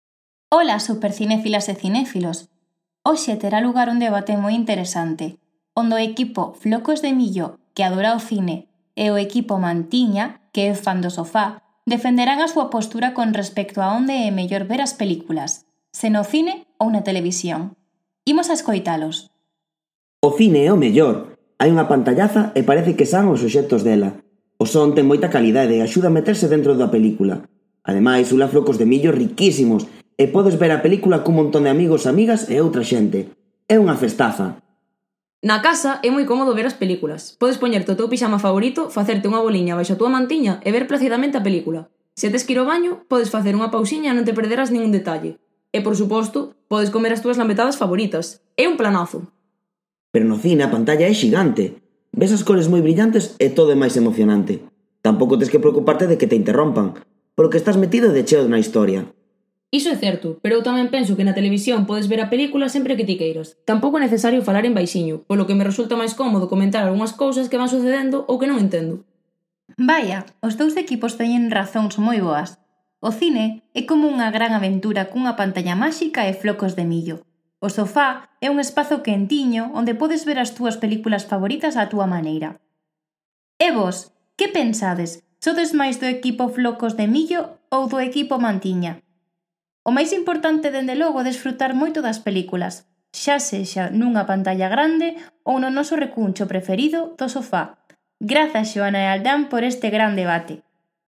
Escoitade o seguinte audio no que dúas persoas debaten sobre un tema.
Debate_flocos_millo_mantina.mp3